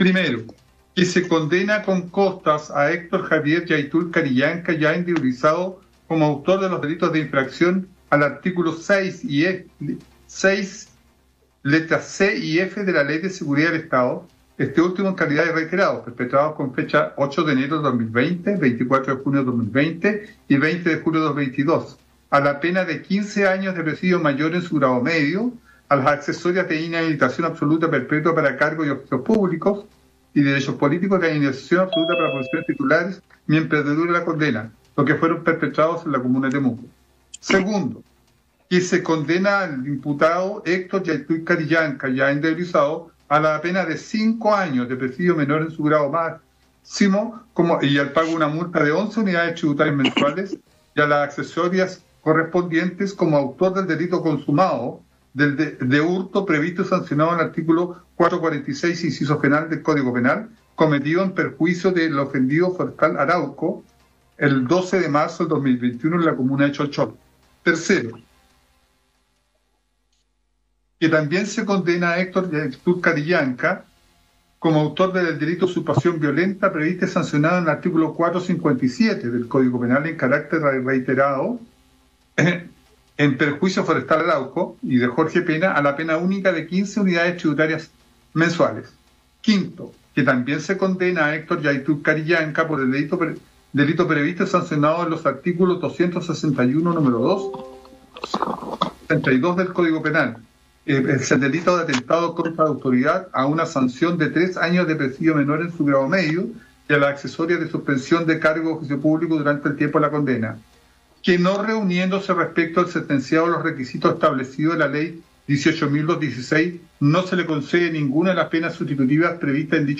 El juez Jorge González, relator de la audiencia el día 7 de mayo, precisó los detalles de la condena contra el comunero mapuche, Héctor Llaitul.